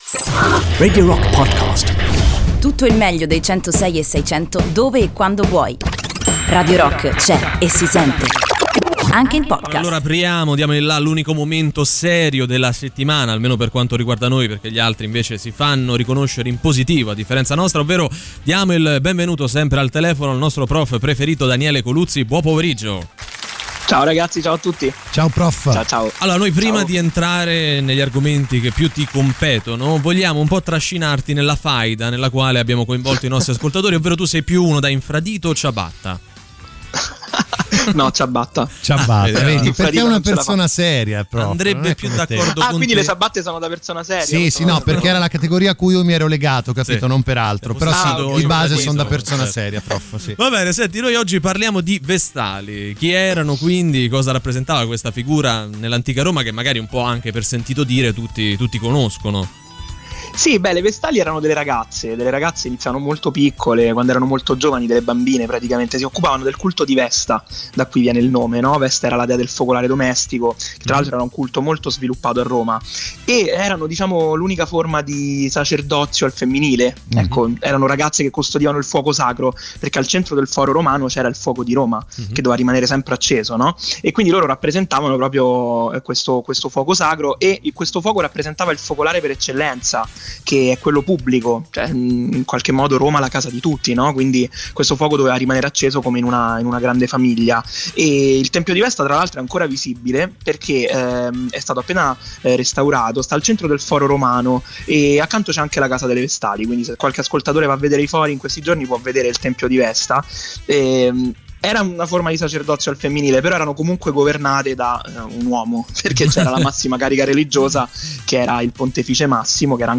interviene in diretta su Radio Rock